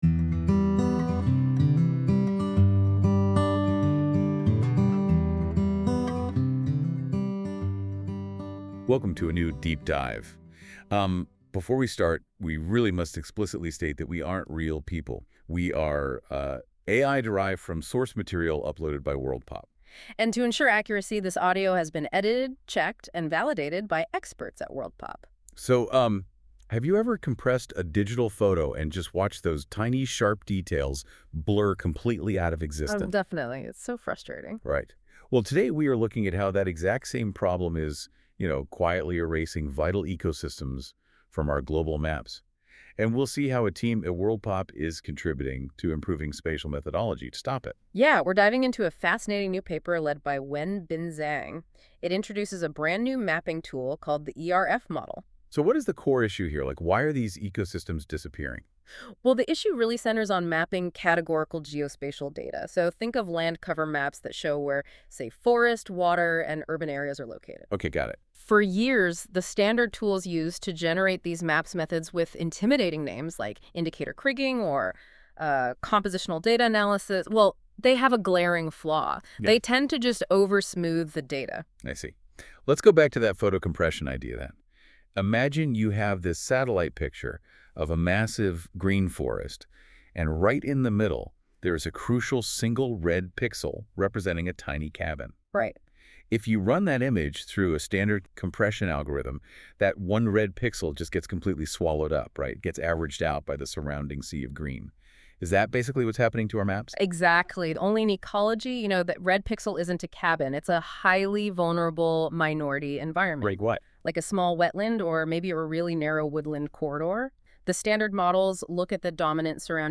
This feature uses AI to create a podcast-like audio conversation between two AI-derived hosts that summarise key points of the document - in this case the full journal article linked above.
Music: My Guitar, Lowtone Music, Free Music Archive (CC BY-NC-ND)